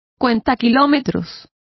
Complete with pronunciation of the translation of odometer.